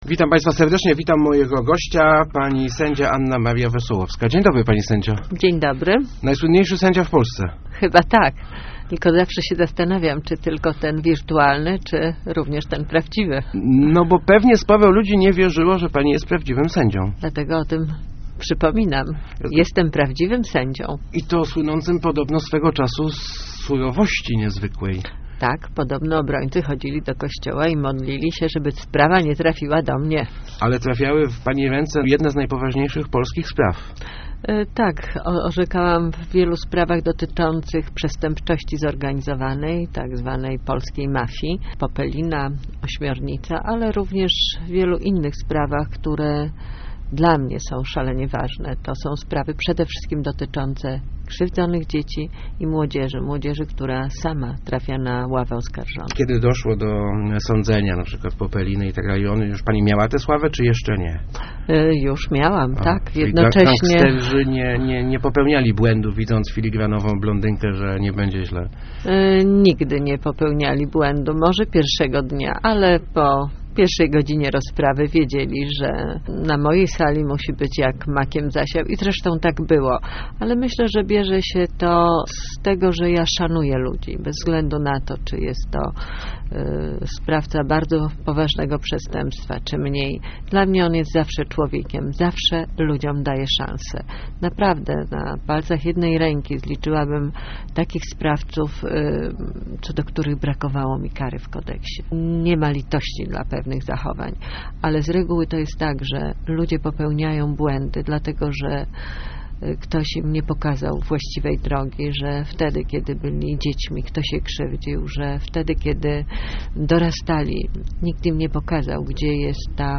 Program był oparty na prawdziwych sprawach sądowych, choć nieraz wydawałam inne wyroki - mówiła w Rozmowach Elki sędzia Anna Maria Wesołowska, słynna z telewizyjnego show.